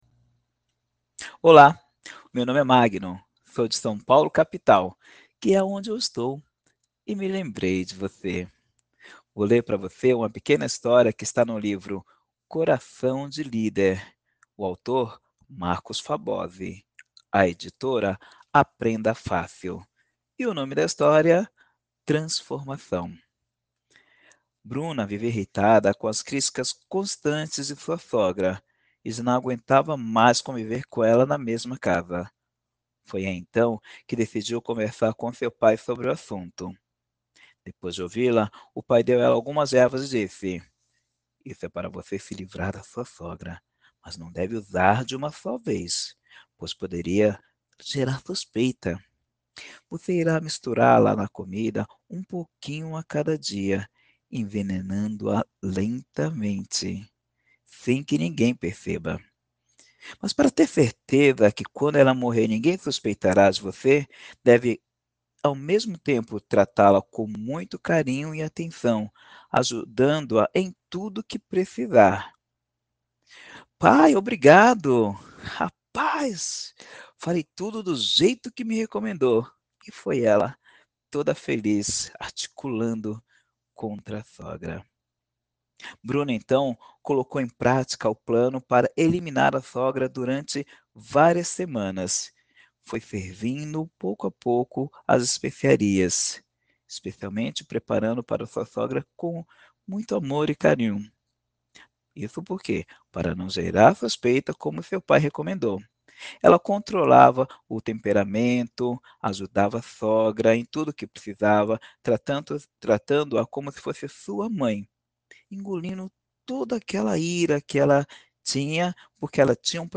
Trecho do Livro “Coração de Líder”